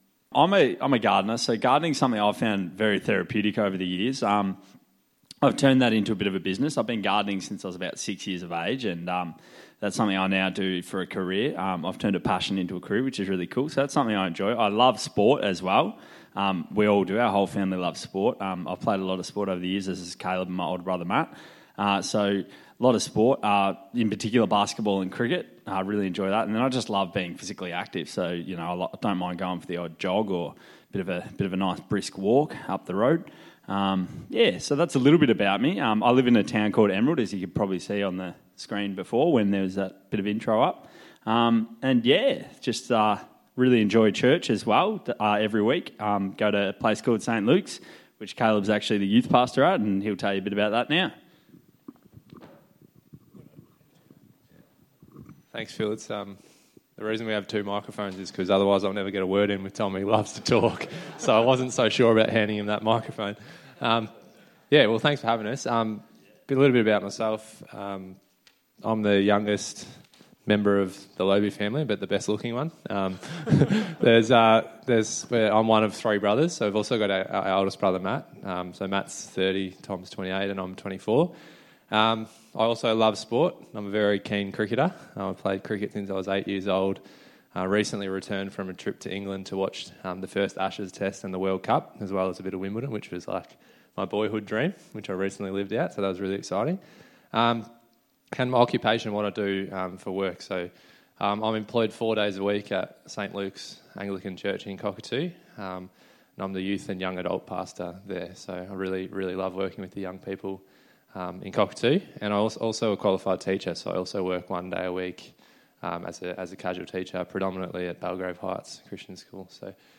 Men's Brunch Guest Speaker